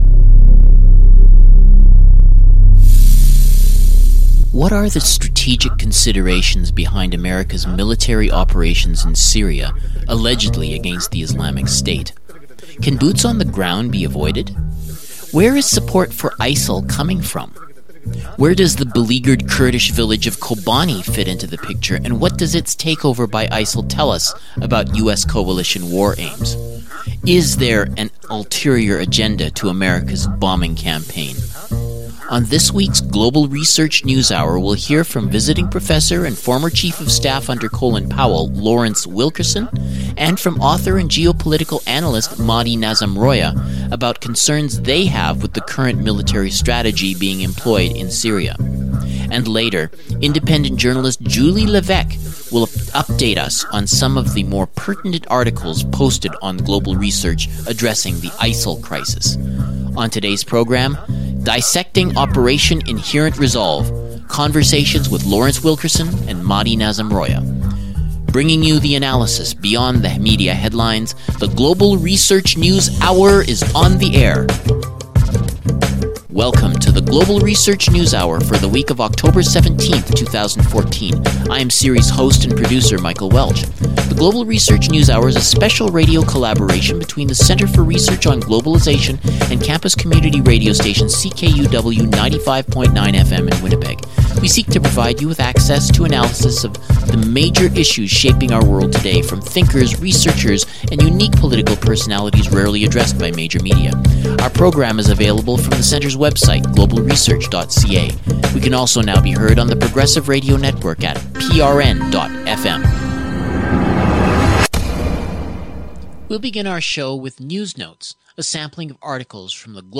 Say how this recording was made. File Information Listen (h:mm:ss) 0:59:31 Global_REsearch_News_Hour_episode_78_October_17,_2014.mp3 Download (21) Global_REsearch_News_Hour_episode_78_October_17,_2014.mp3 57,145k 0kbps Stereo Listen All